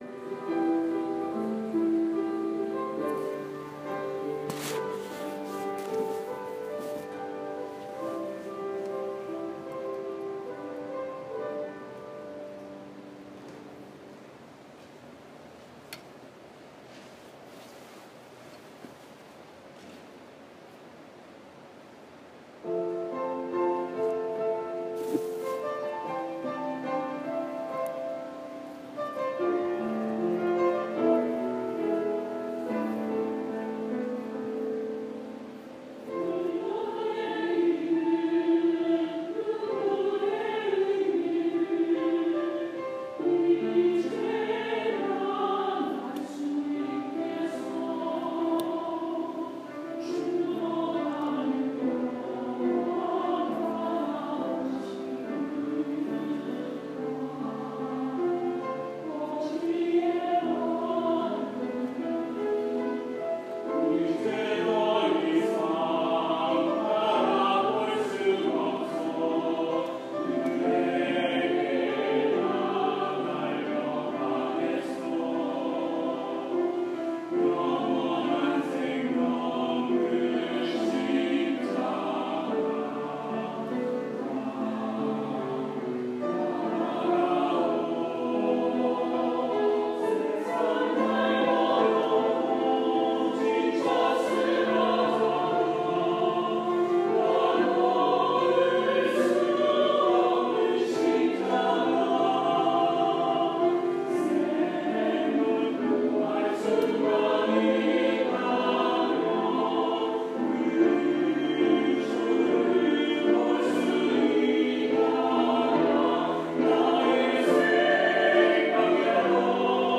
8월 7일 주일 찬양대 찬양(사모곡, 이권희 작곡)